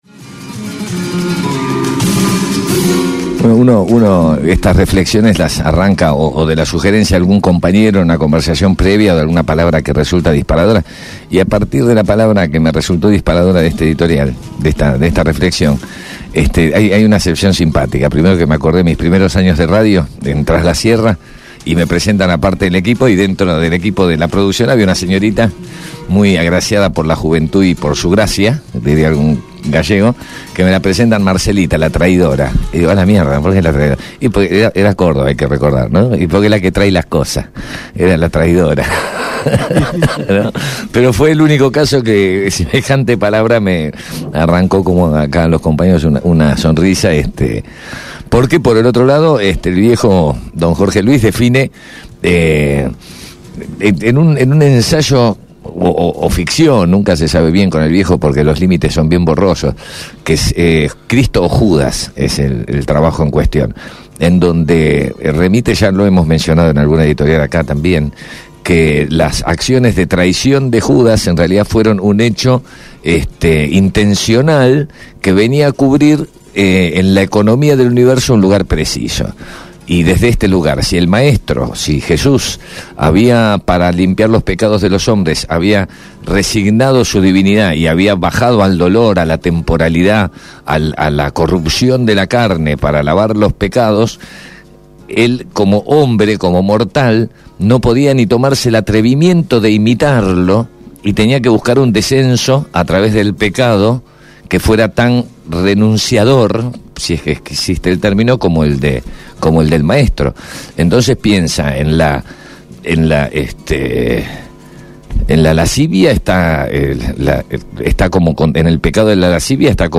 Editorial